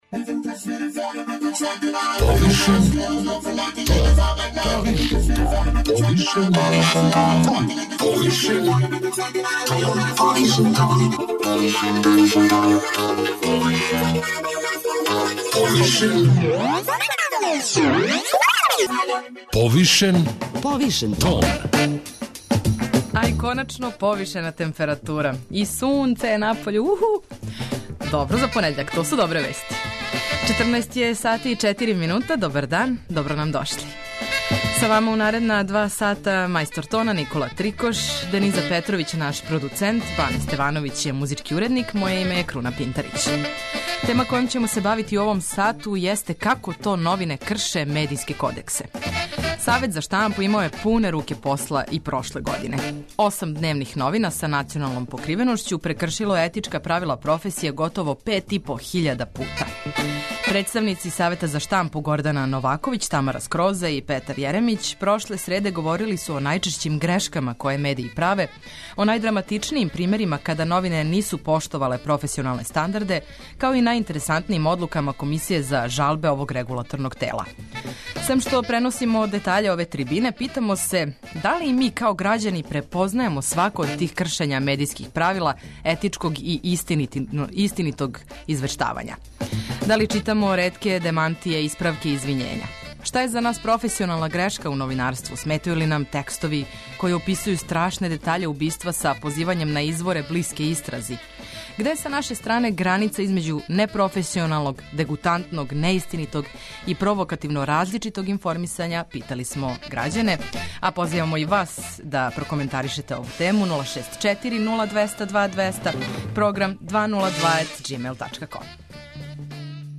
Сем што преносимо детаље ове трибине, питамо се: да ли и ми, као грађани, препознајемо свако од тих кршења медијских правила етичког и истинитог извештавања?